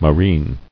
[mo·reen]